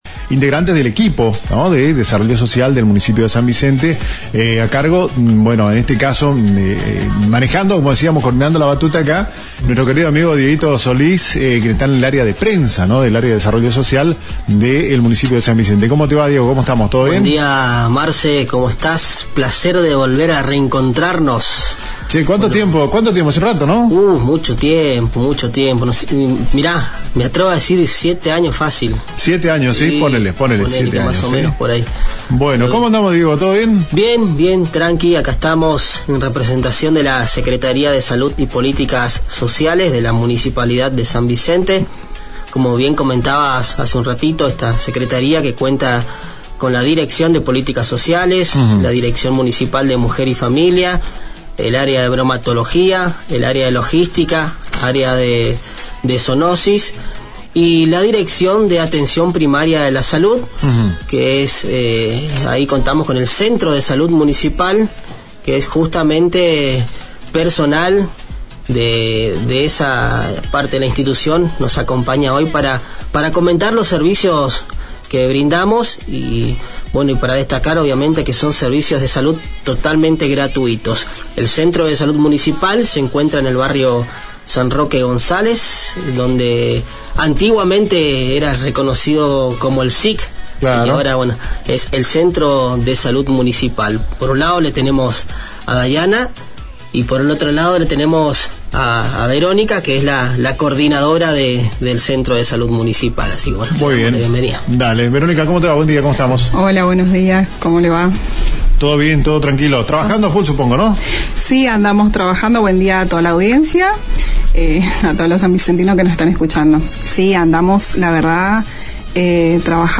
Entrevista al equipo de Salud de la Secretaría de Poílicas Sociales San Vicente -
Nota realizada en el programa “AGENDA 360”, FM Top 101.5 MHz, San Vicente